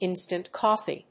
/ˌɪnstənt ˈkɒfi(英国英語)/